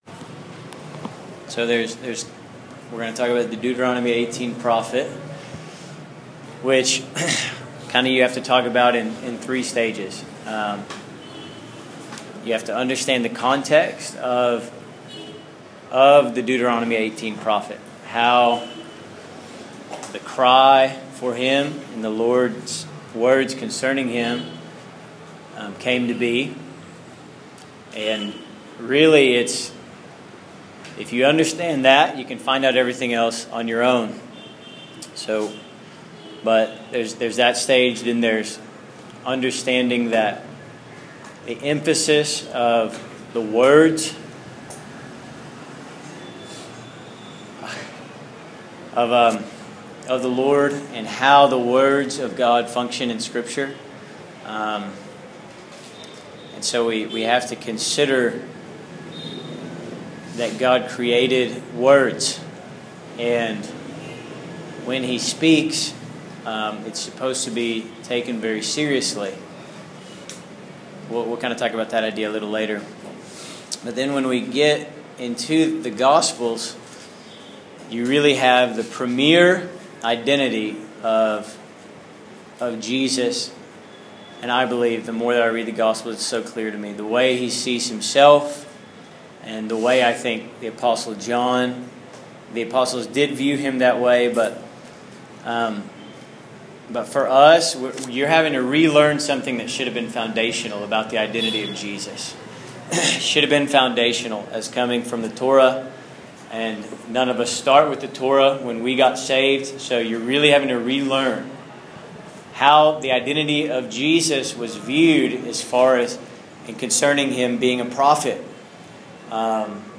This audio teaching is in partnership with the progression of posts entitled The Gospel from Genesis to Revelation Session #10.